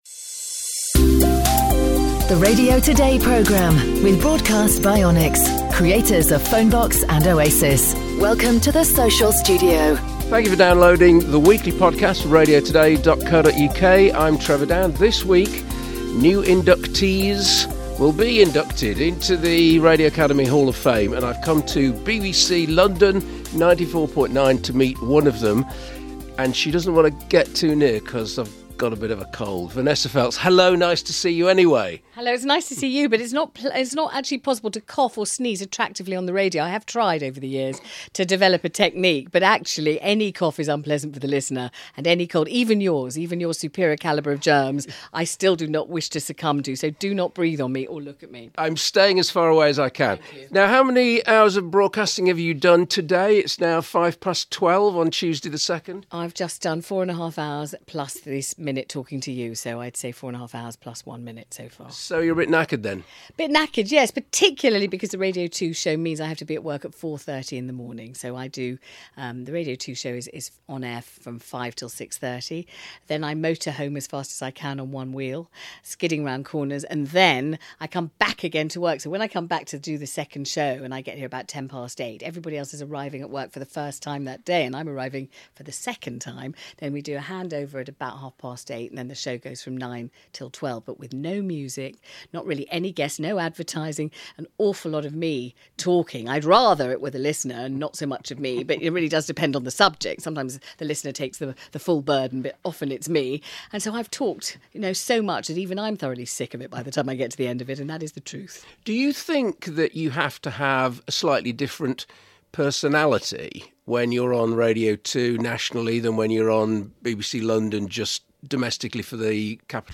Trevor Dann meets new Radio Academy Hall of Fame inductee Vanessa Feltz who talks about working for two stations every day (Radio 2 and BBC London 94.9), her early days on GLR's Jewish London and why she hopes Jeremy Vine or Simon Mayo get new jobs soon. Plus news and Radio Moments.